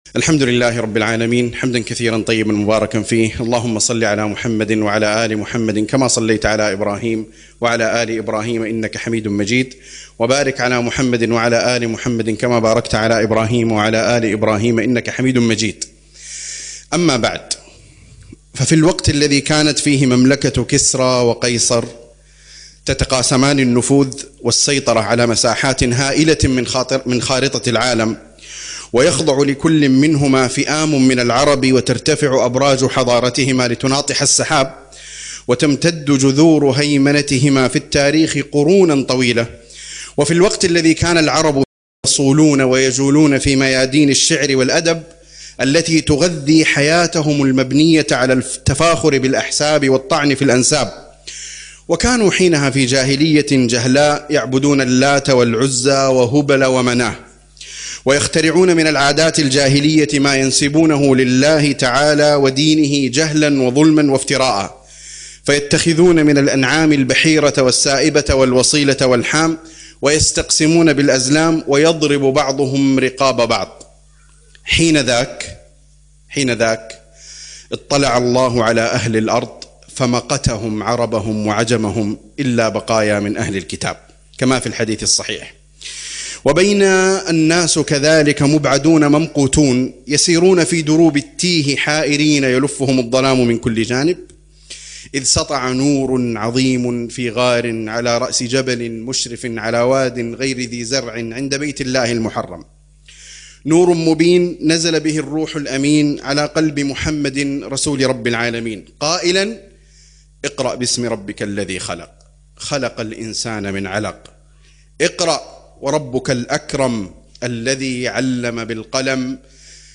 من جيل الأمناء إلى جيل التجديد _ كلمة حفل أمداد